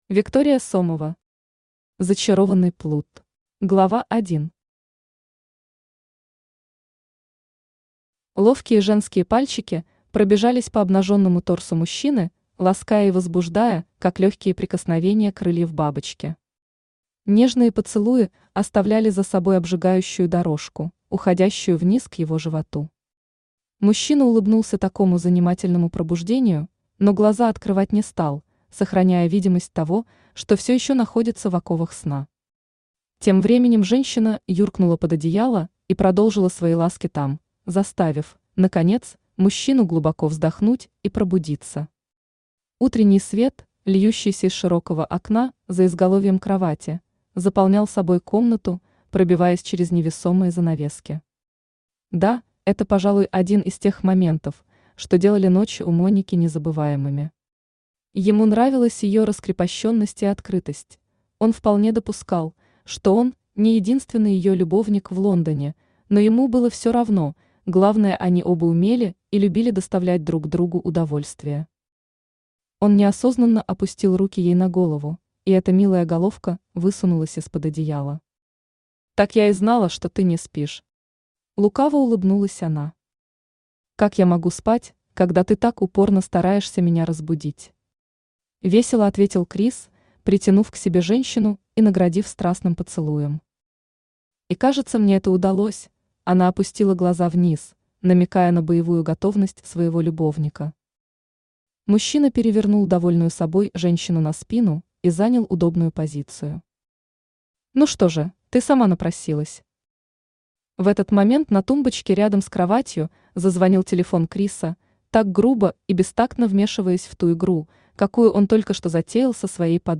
Aудиокнига Зачарованный плут Автор Виктория Сомова Читает аудиокнигу Авточтец ЛитРес.